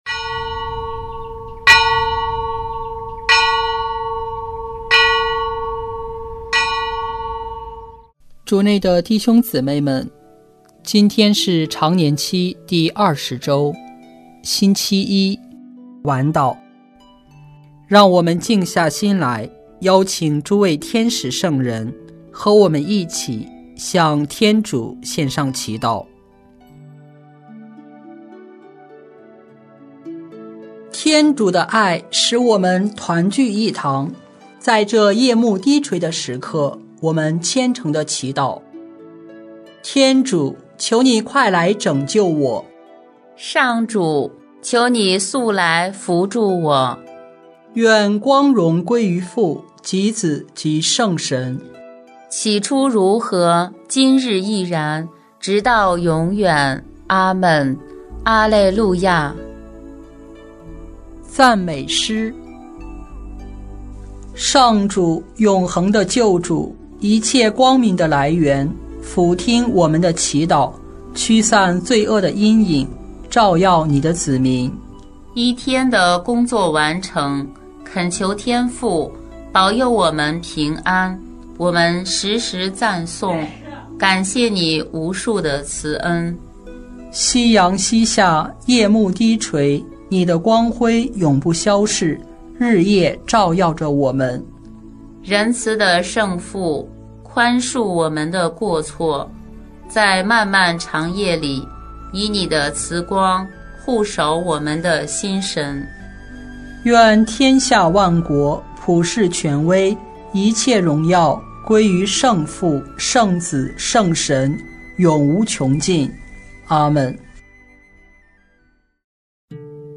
圣咏吟唱 圣咏 135 逾越节赞美诗 “传述上主的丰功伟业，就是赞美上主。”